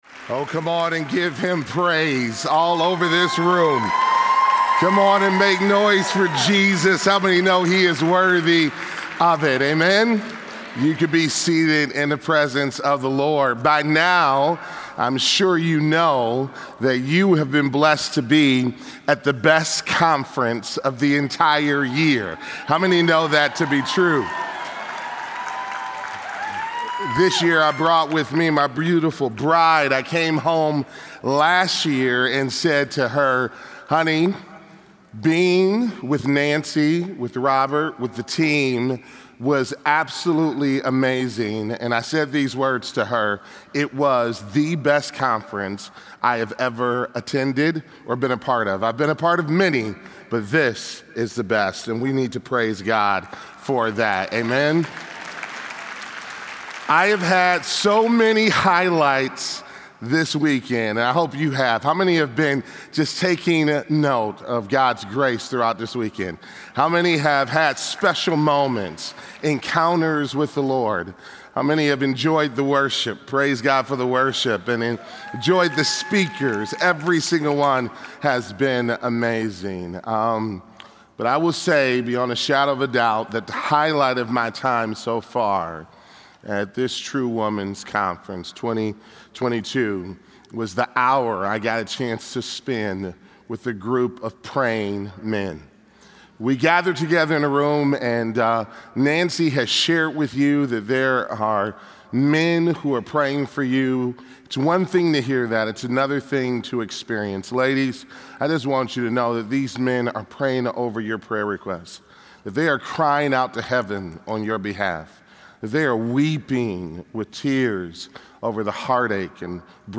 Finding Your Mission in Heaven’s Rule | True Woman '22 | Events | Revive Our Hearts